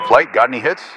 Added .ogg files for new radio messages
Radio-playerWingmanReportContacts2.ogg